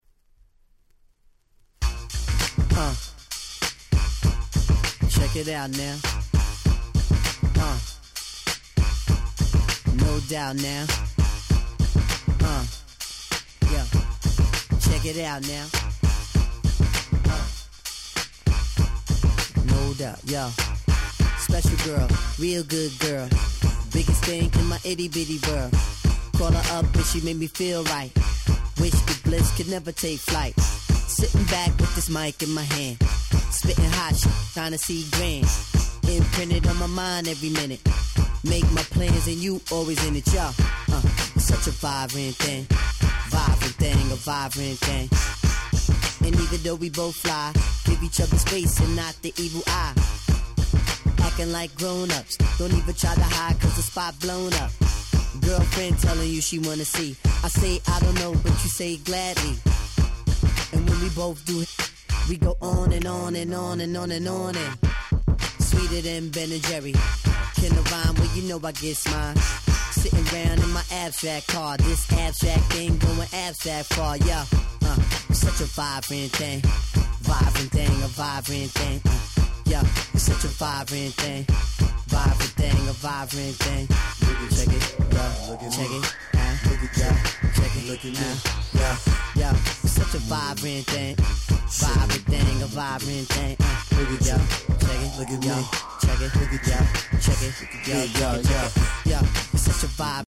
Late 90's Hip Hop Classics !!